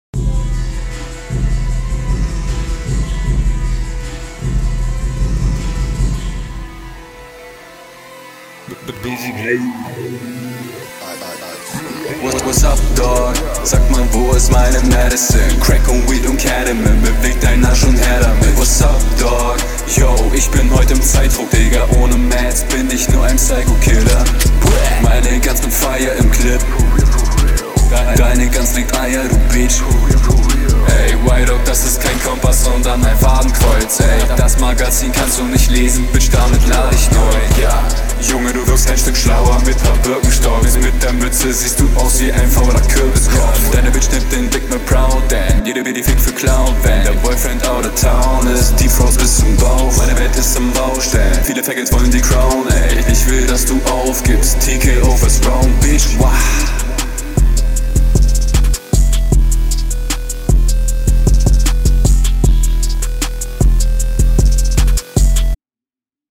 Cooler Vibe, Flow gut, kommst smooth übern Beat.
Finde flowlich kommt das sehr nice und ich mag auch den Tune drin.